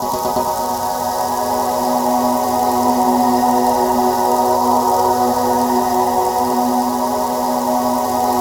SANDSHOWER-R.wav